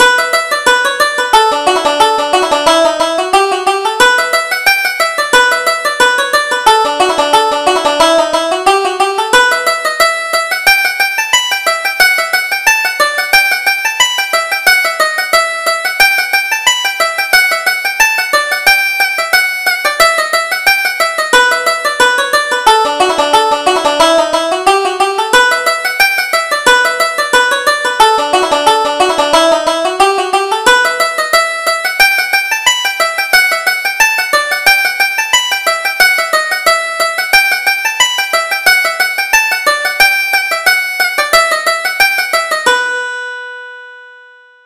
Reel: The Ships Are Sailing